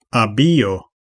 Ääntäminen
US : IPA : [fɜː(r)]
amerikanenglanti